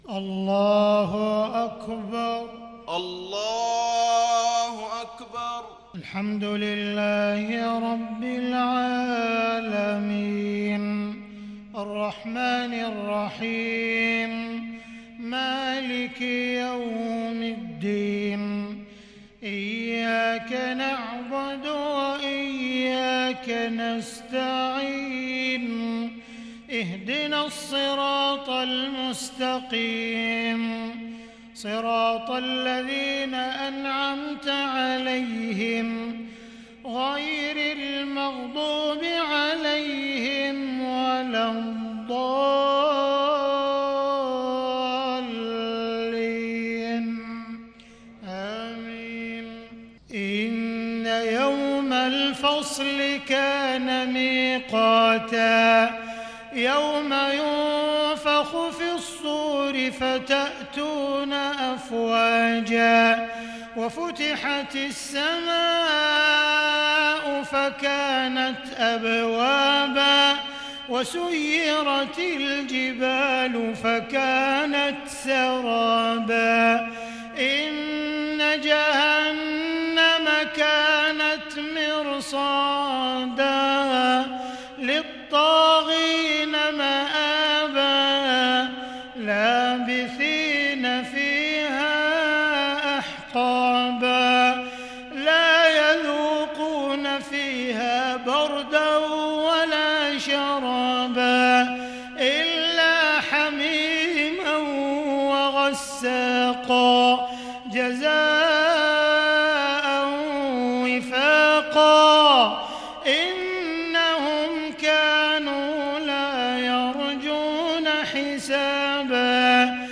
صلاة العشاء للشيخ عبدالرحمن السديس 18 صفر 1442 هـ
تِلَاوَات الْحَرَمَيْن .